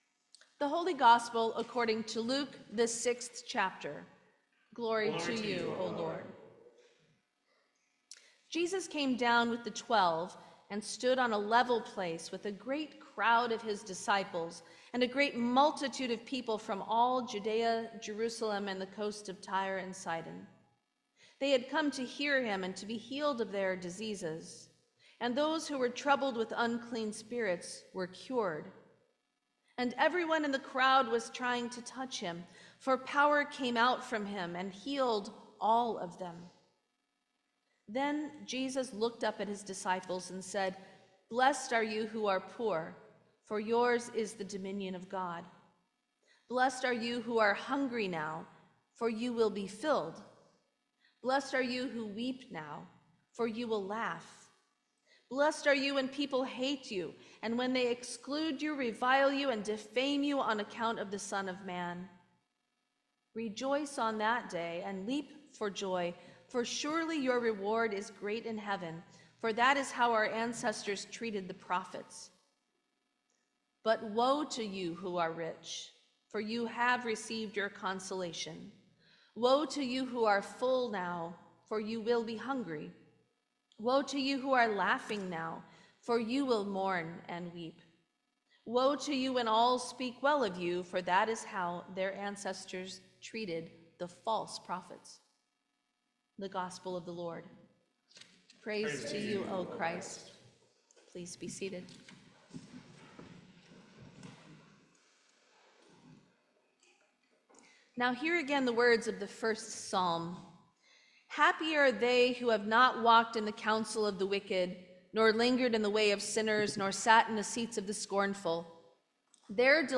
Sermon for the Sixth Sunday after Epiphany 2025